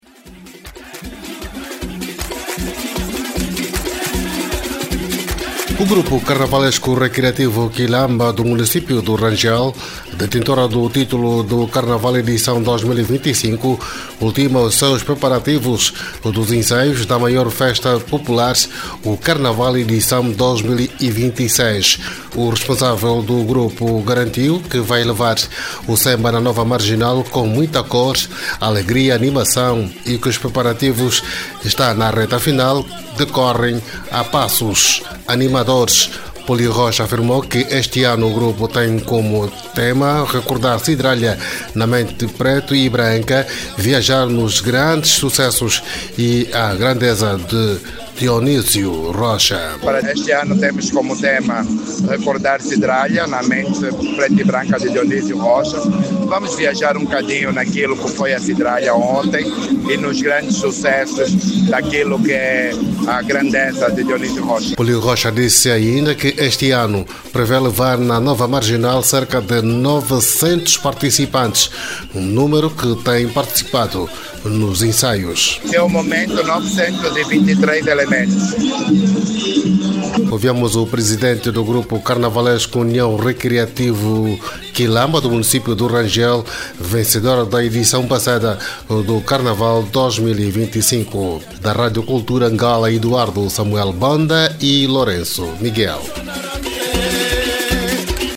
A poucos dias da maior manifestação do país, os grupos carnavalescos preparam-se ao pormenor com ensaios diários. A reportagem da Rádio Nacional de Angola fez, na quarta-feira, uma ronda aos grupos para ver como andam os ensaios.